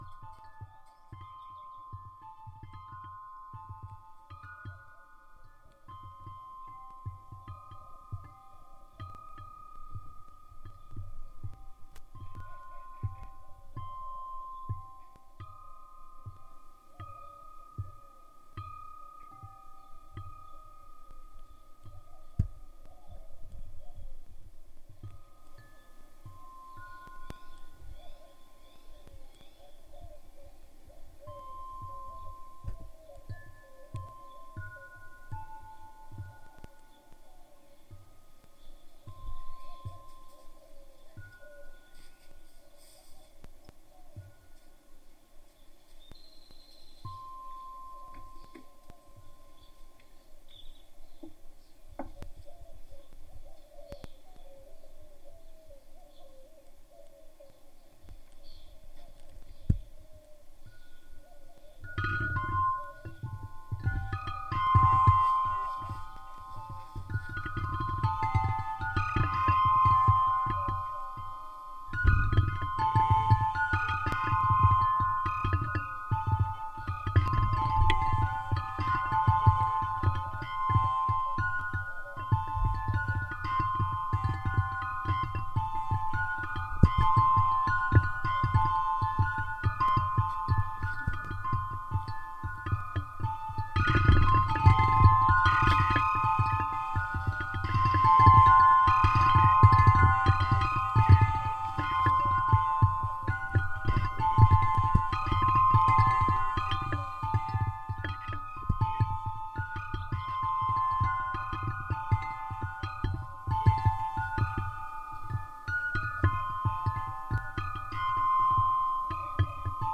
风铃联系
描述：在德克萨斯州奥斯汀的一个刮风的日子里，在windchimes上联系麦克风
Tag: 报时 管状 环状 金属的 振铃 风铃